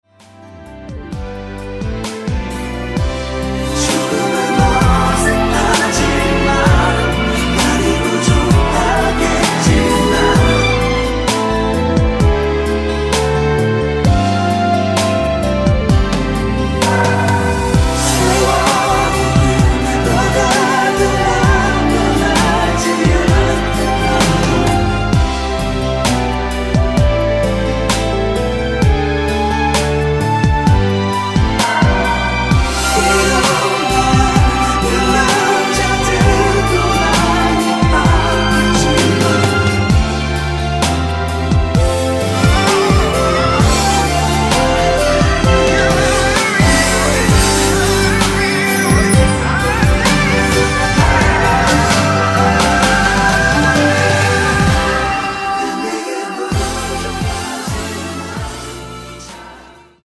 코러스 포함된 MR 입니다.(미리듣기 참조)
앞부분30초, 뒷부분30초씩 편집해서 올려 드리고 있습니다.